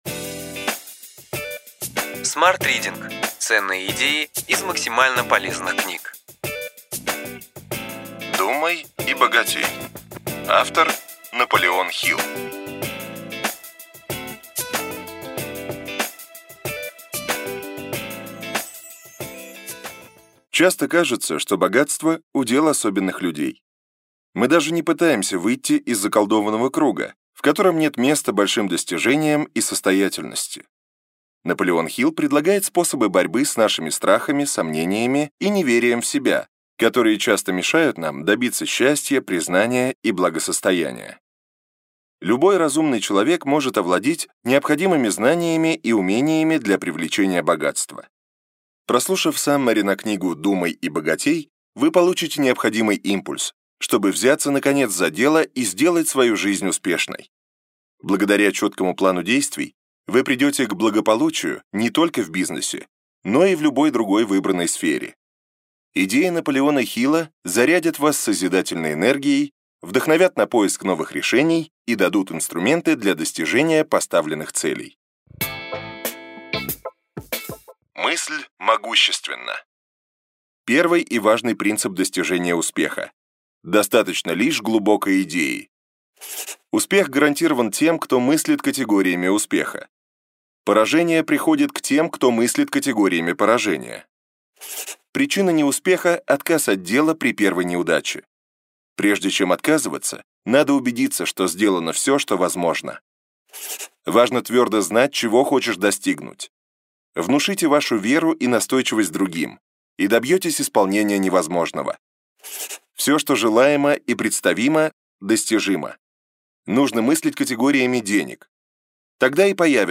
Аудиокнига Ключевые идеи книги: Думай и богатей. Наполеон Хилл | Библиотека аудиокниг